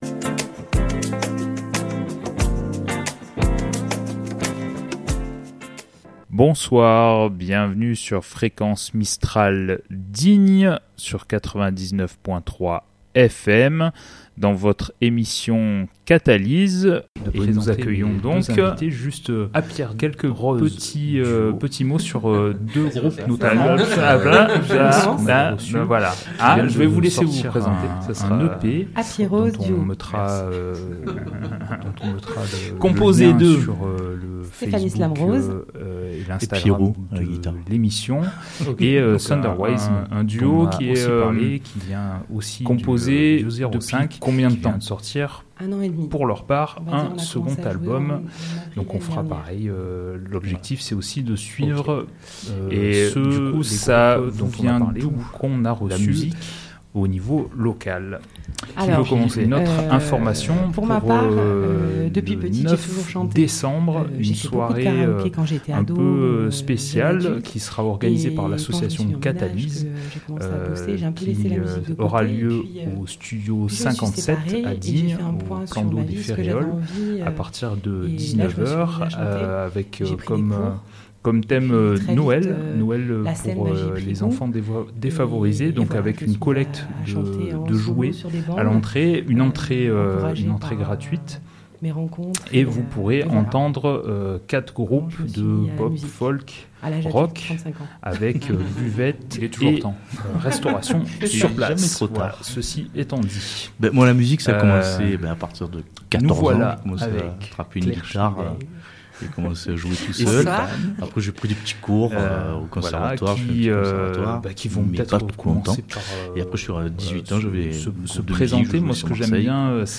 Malheureusement, 2 sons se superposent en tout début d'émission, jusqu'à 2'44...